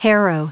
Prononciation du mot harrow en anglais (fichier audio)
Prononciation du mot : harrow